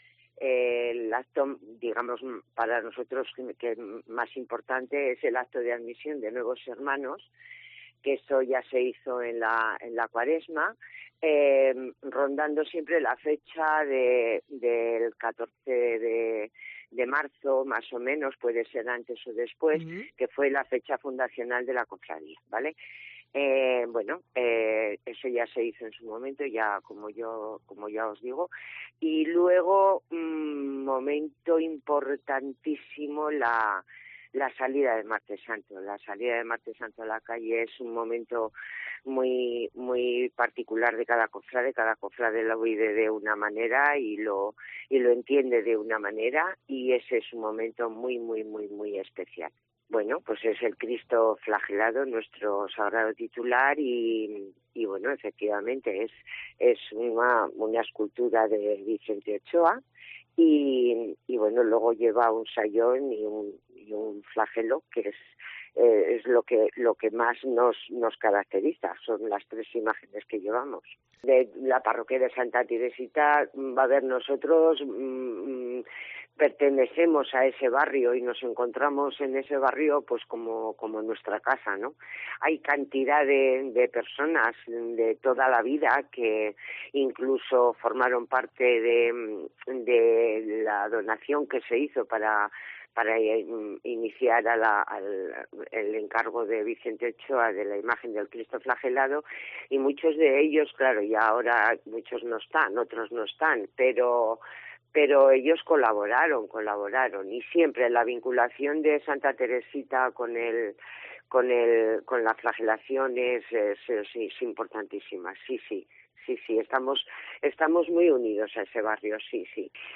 relata emocionada en COPE el fervor del Martes Santo.